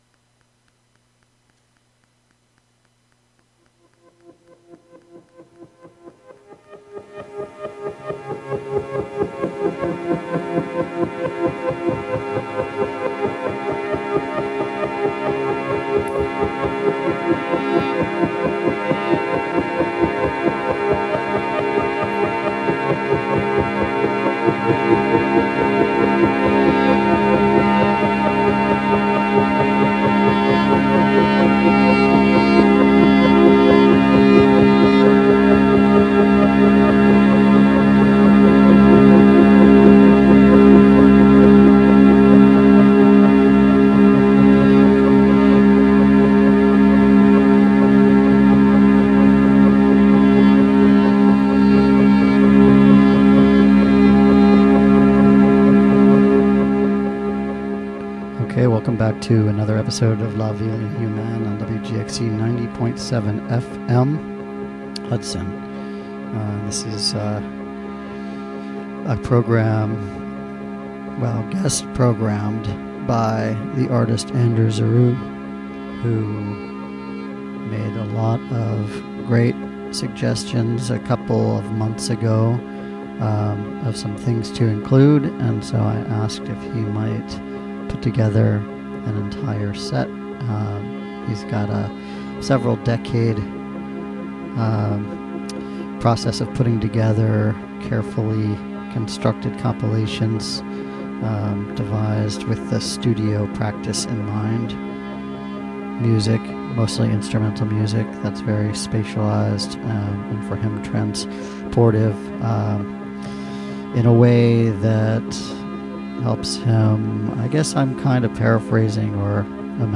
On this show, you will hear songs and sounds from a variety of genres as well as from unclassifiable styles of music and experimentation. The show will sometimes feature live performances from near and far and periodically unpredictable guests will join to share music.